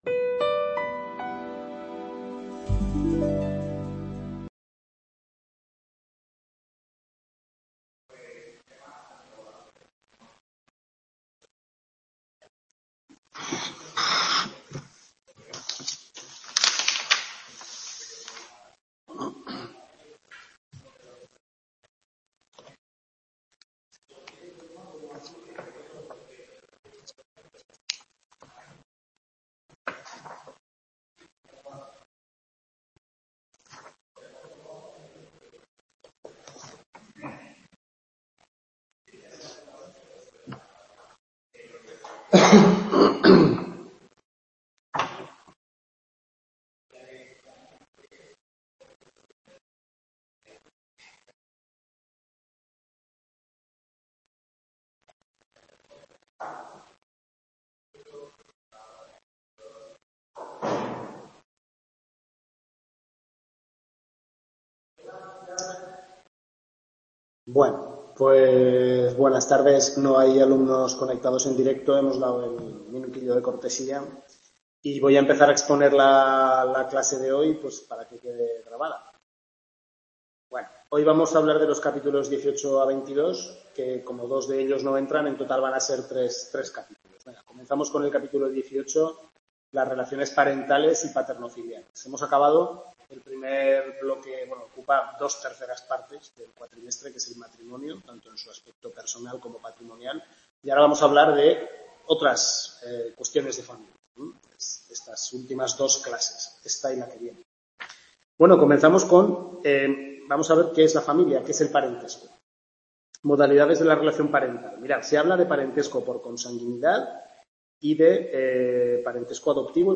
Tutoría 5/6 segundo cuatrimestre Civil I (Derecho de Familia), centro UNED Calatayud, capítulos 18 a 22 del Manual del Profesor Lasarte